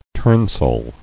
[turn·sole]